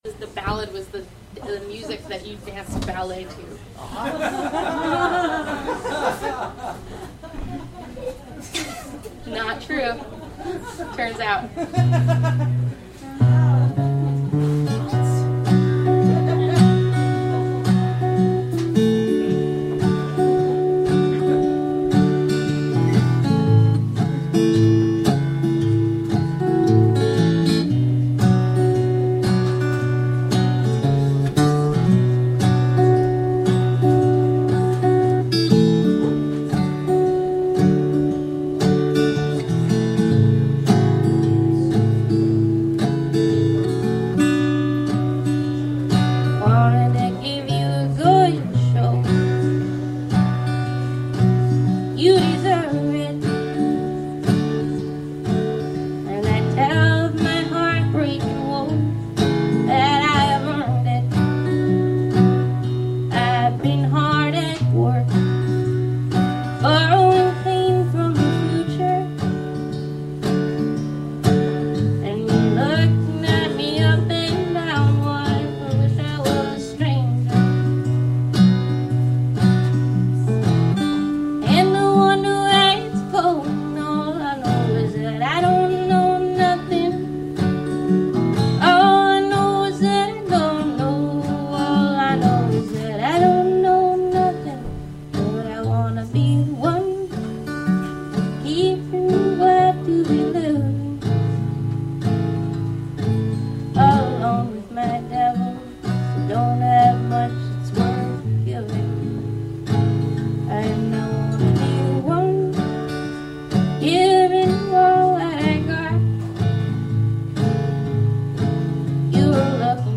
Live performances for Hudson Valley-based performe...